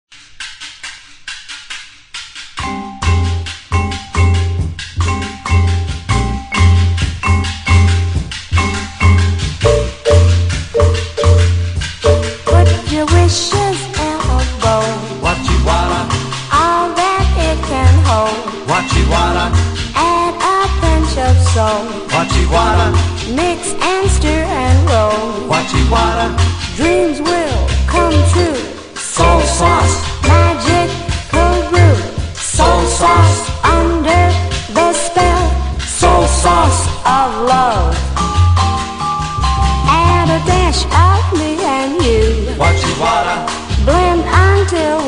フルートの繊細なメロディーに絡むヴィヴラとオルガンの軽快なラテンの旋律が最高です。